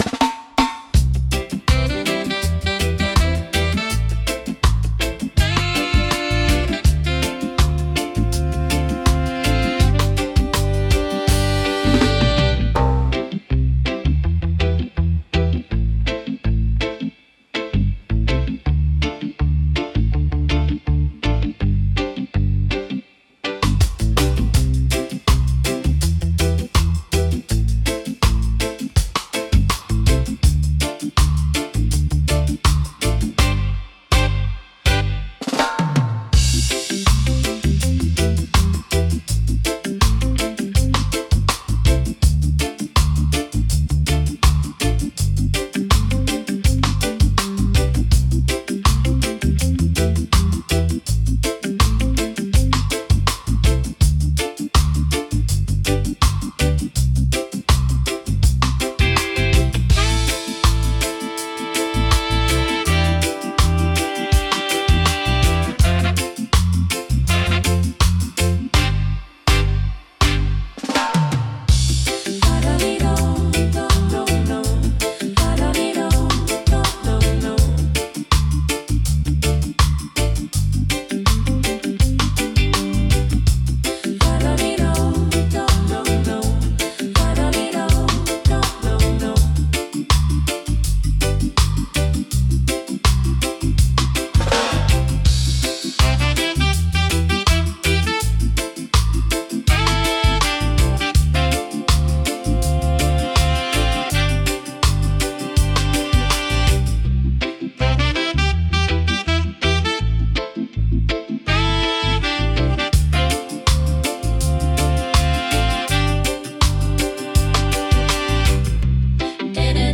レゲエ特有のベースラインとドラムパターンが心地よいグルーヴを生み出します。
ナチュラルで陽気な雰囲気を演出し、聴く人に穏やかで楽しい気分をもたらします。